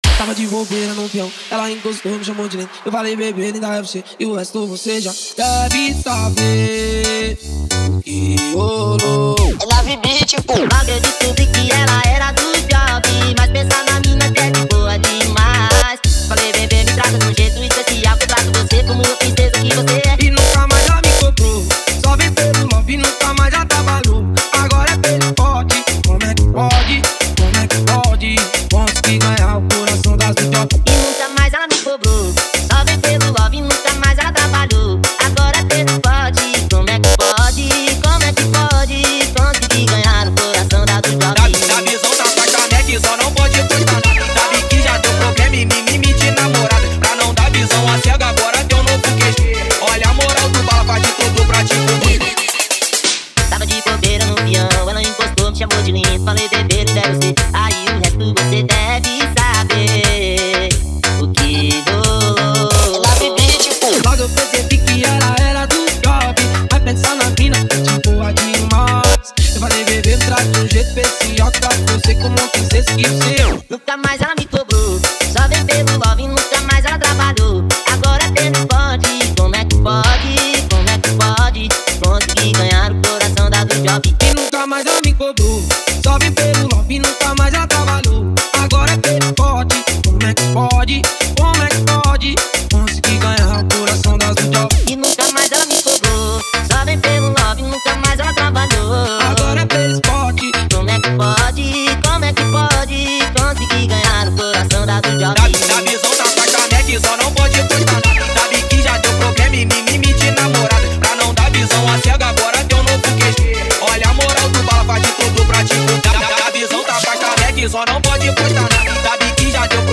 OUÇA NO YOUTUBE Labels: Tecnofunk Facebook Twitter